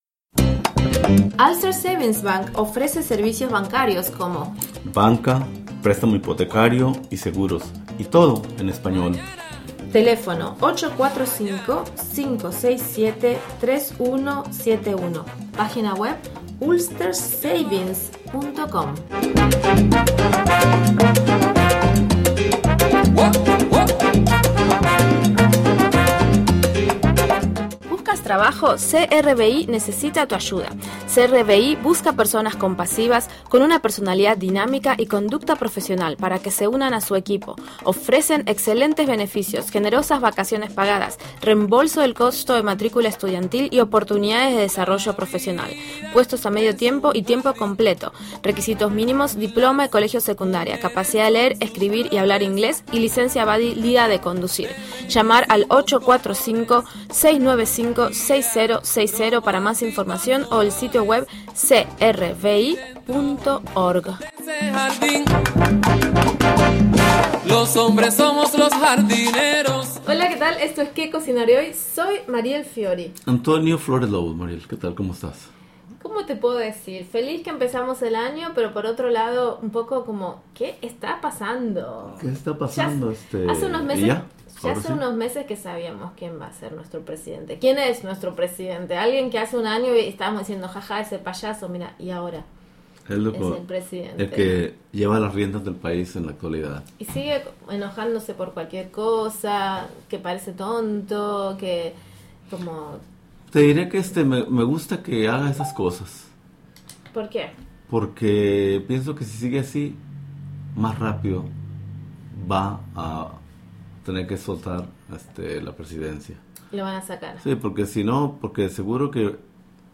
9am Un programa imperdible con noticias, entrevistas,...